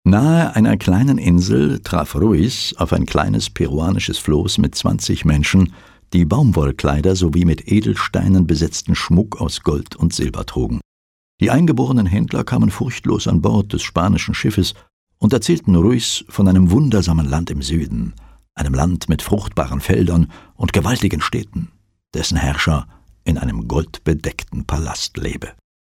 Computer Bild Spiele 1/2003: ”Professional actors lead through the story.”
Historical knowledge is imparted to players through gripping voice-overs.”
Geschichte lernen: